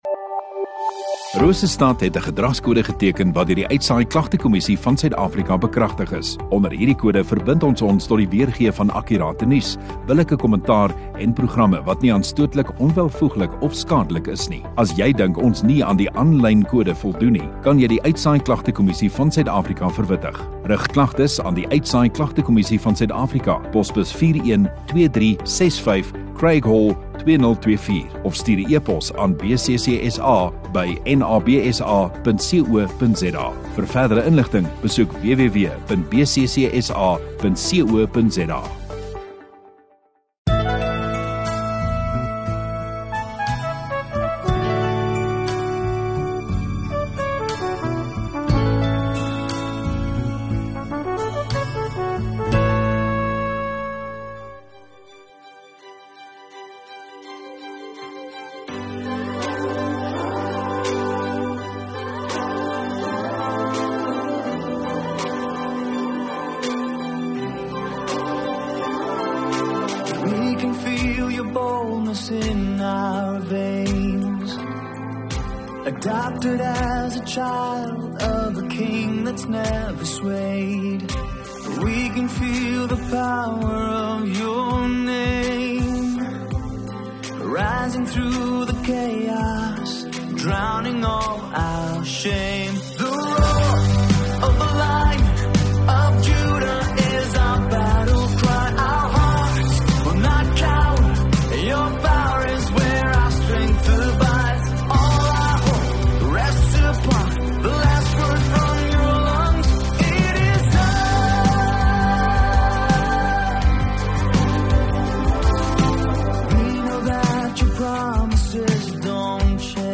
21 Oct Saterdag Oggenddiens